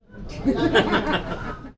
Risas de gente suaves